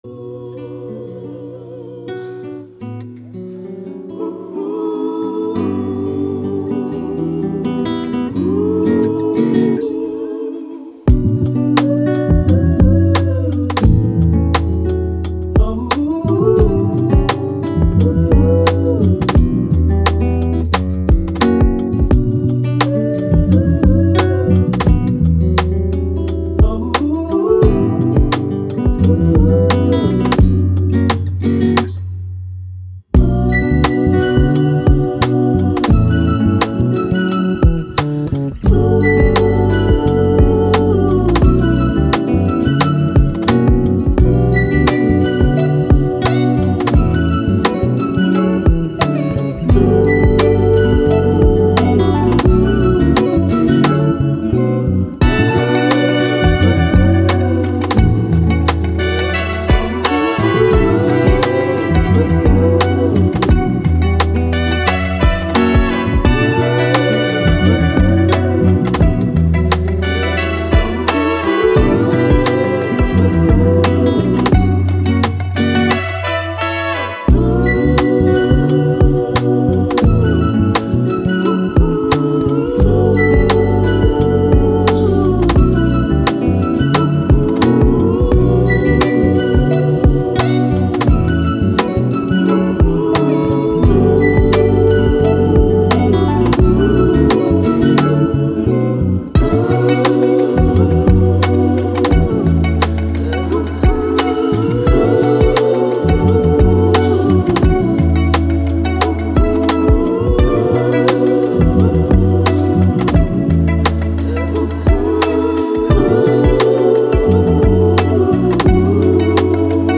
Vintage, Vibe, Thoughtful, Chilled